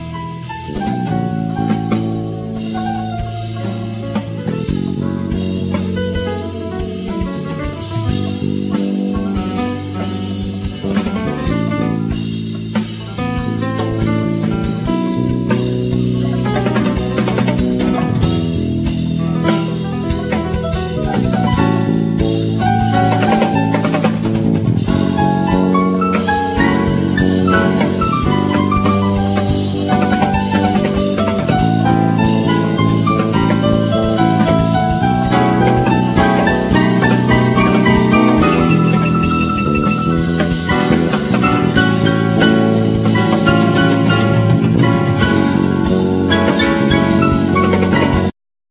Bass
Keyboards
Trumpet
Guitar
Drums
Percussions
Trombone